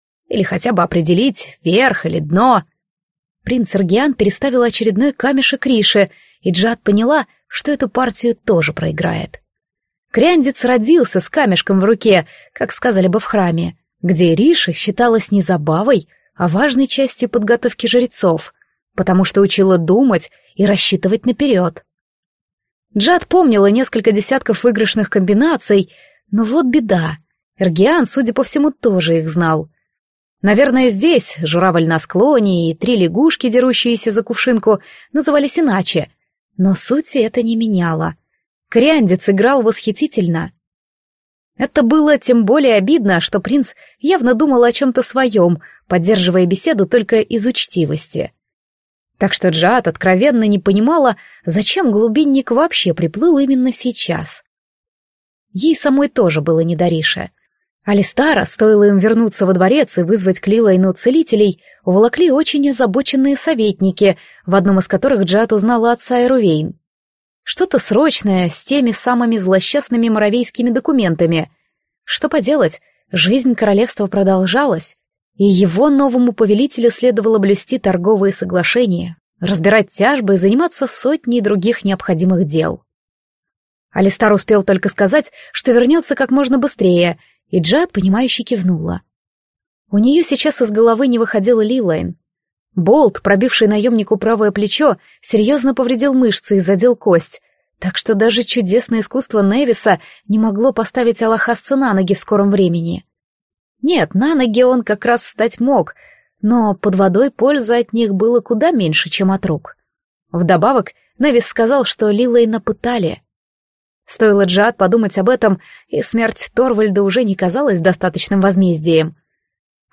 Аудиокнига Сердце морского короля | Библиотека аудиокниг